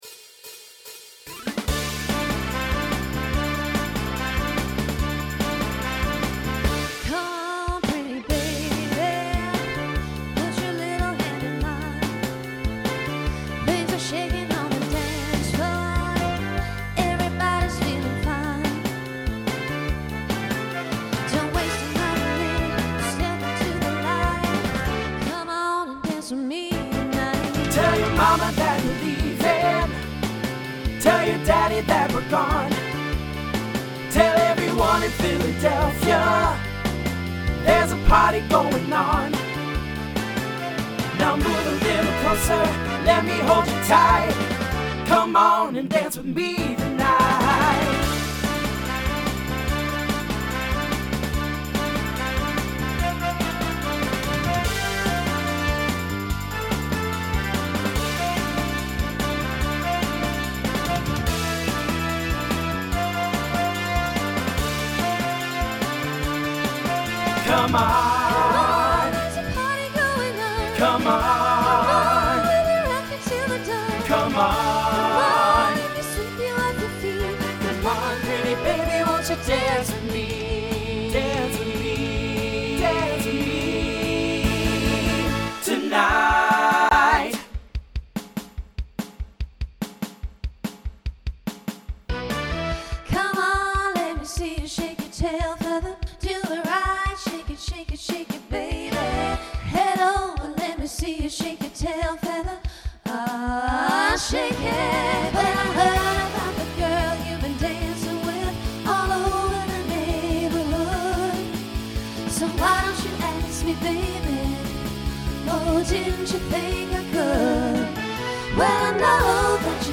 TTB/SSA
Instrumental combo
Pop/Dance